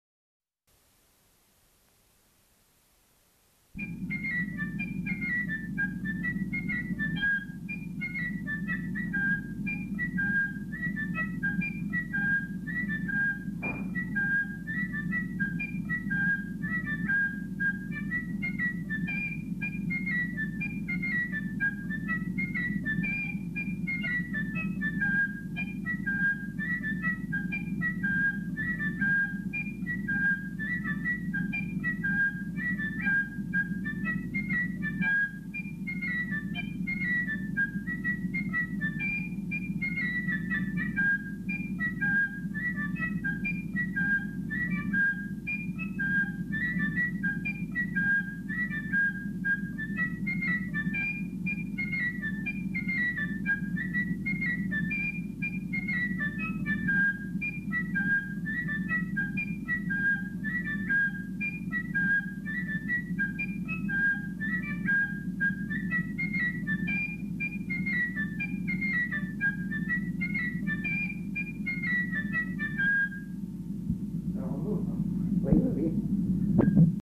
Aire culturelle : Bazadais
Lieu : Cazalis
Genre : morceau instrumental
Instrument de musique : flûte à trois trous
Danse : rondeau